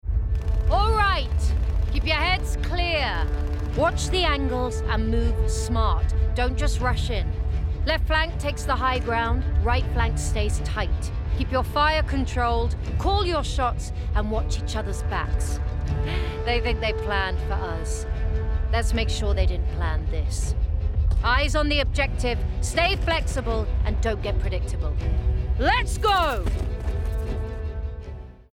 20/30's Neutral/RP,
Husky/Natural/Engaging
Calm Soldier (Neutral)